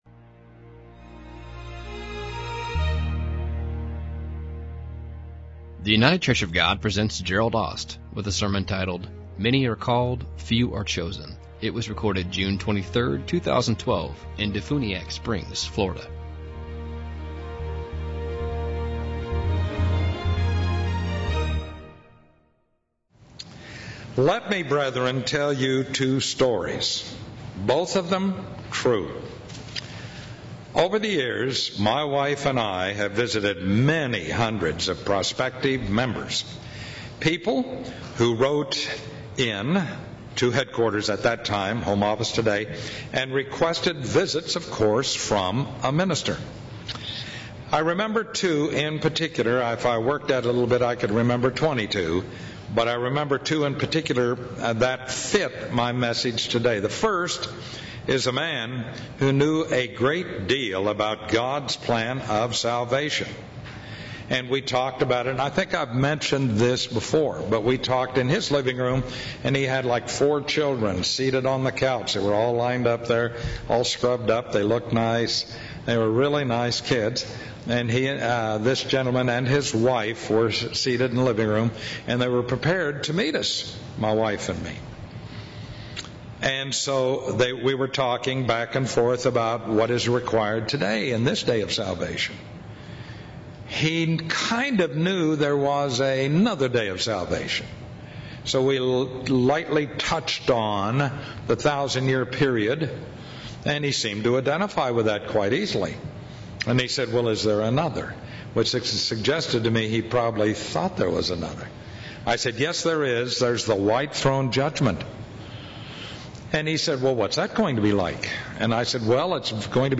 Given in Cincinnati East, OH